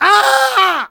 (Крик)
Spy_painsevere01_ru.wav